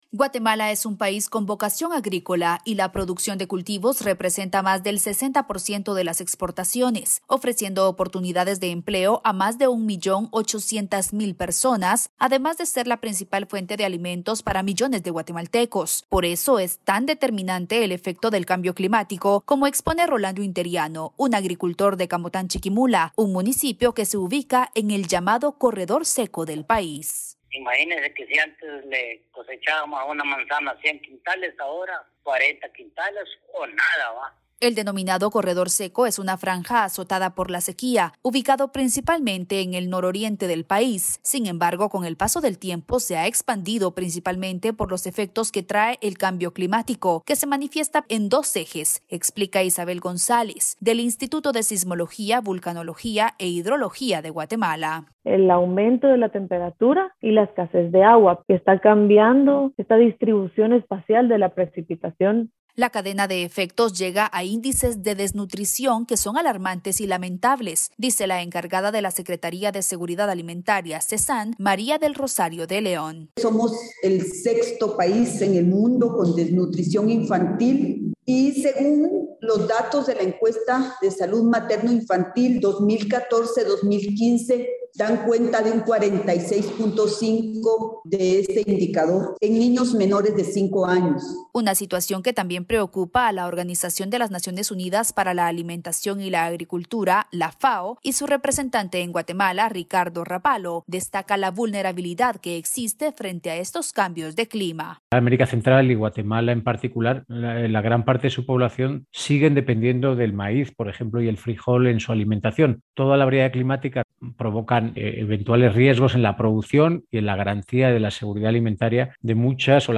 Seguimos con el especial sobre el Cambio Climático con el informe